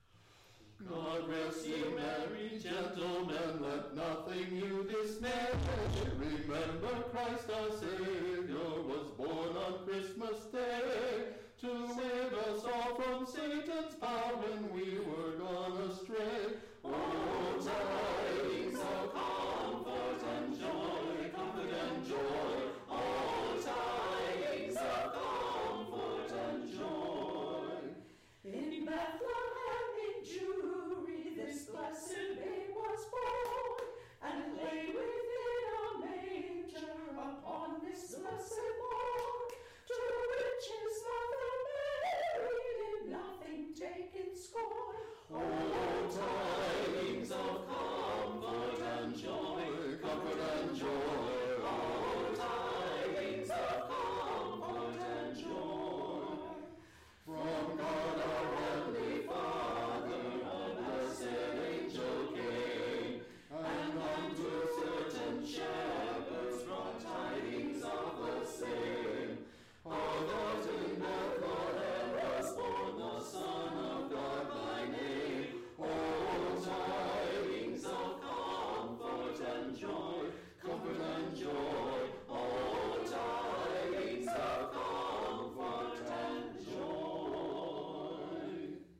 This carol does something that a lot of the traditional classics, and my favorite hymns, that matter, do.